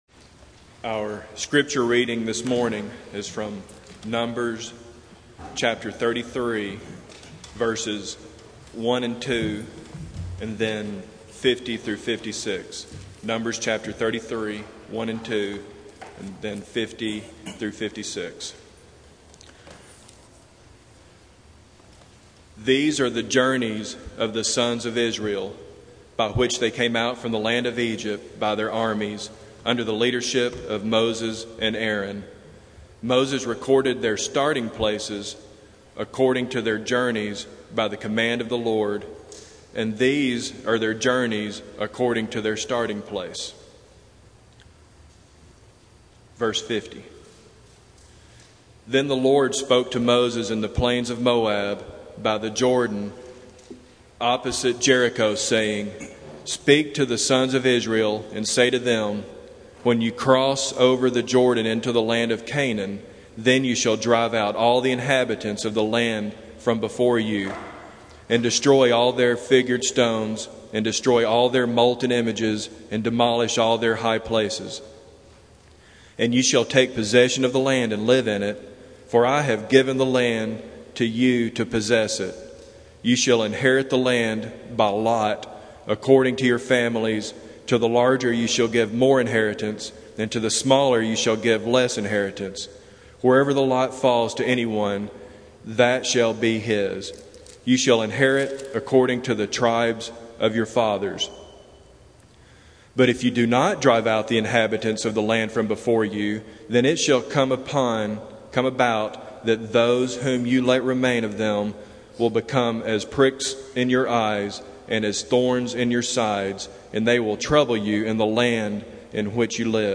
Passage: Numbers 33:1-55 Service Type: Sunday Morning